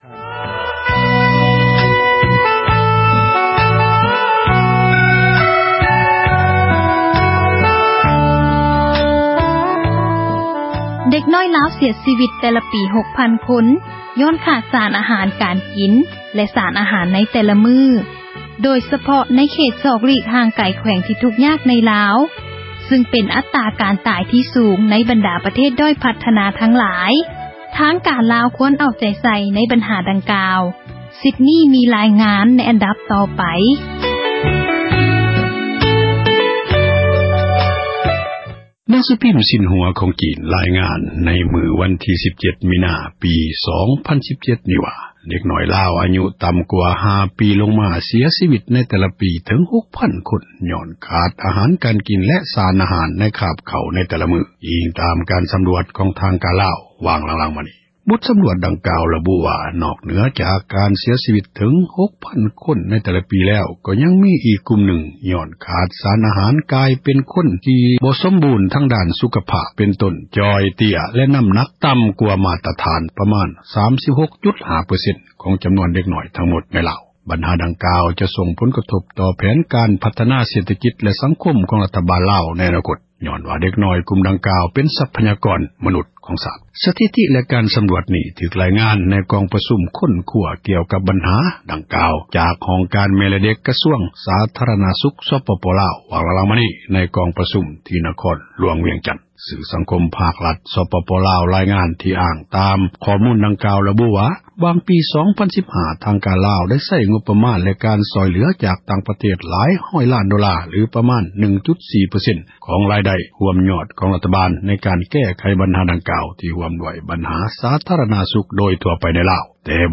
ເດັກນ້ອຍລາວຂາດອາຫານ – ຂ່າວລາວ ວິທຍຸເອເຊັຽເສຣີ ພາສາລາວ